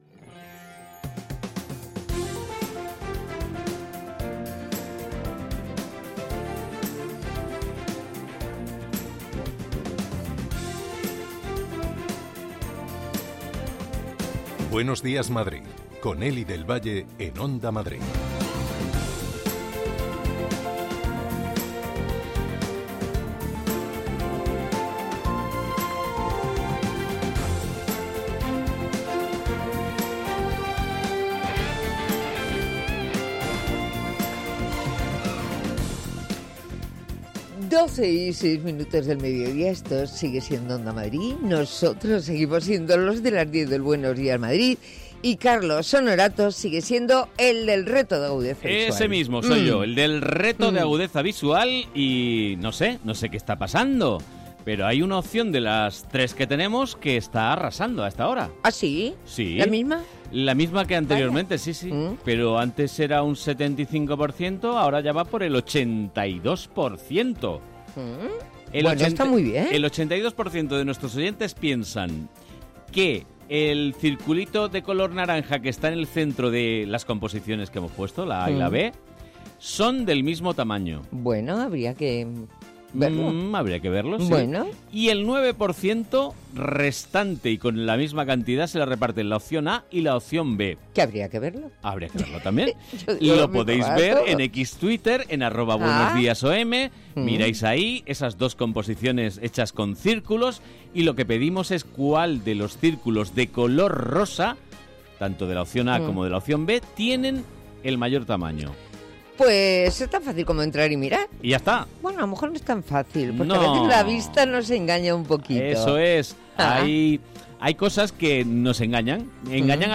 Tres horas más de radio donde se habla de psicología, ciencia, cultura, gastronomía, medio ambiente, consumo.